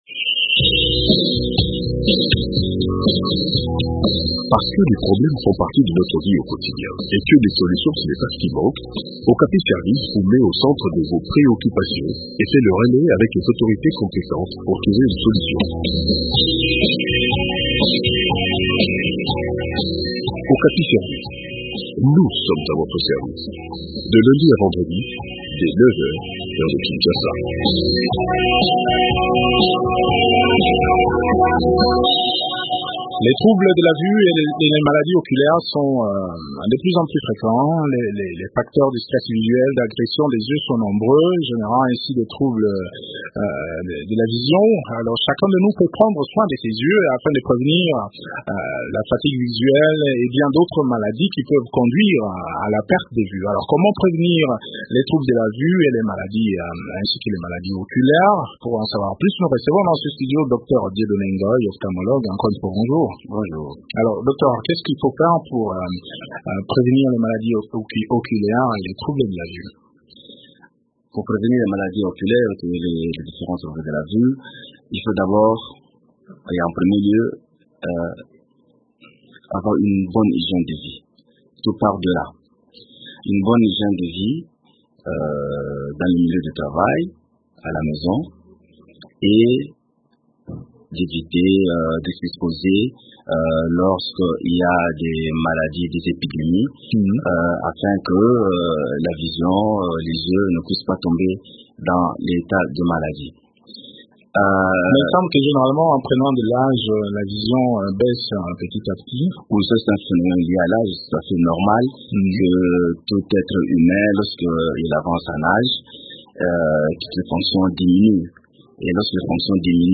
ophtalmologue, répond aux questions des auditeurs